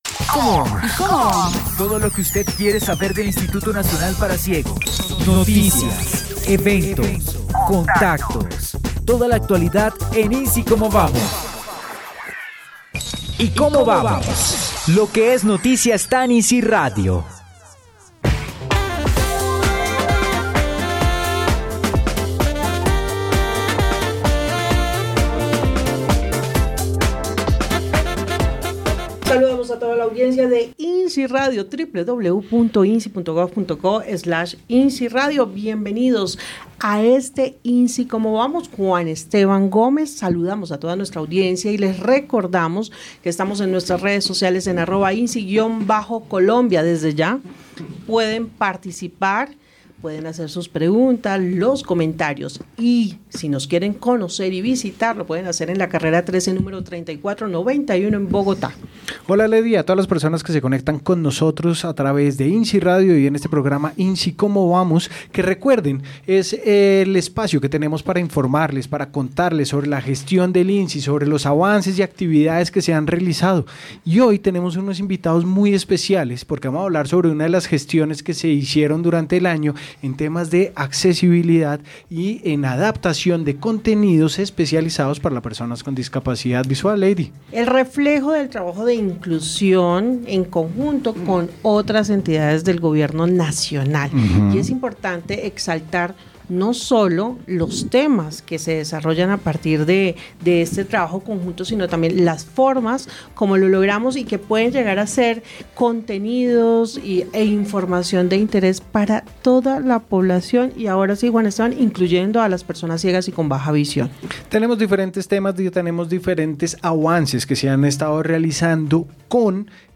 PROGRAMA RADIAL ¿INCI CÓMO VAMOS?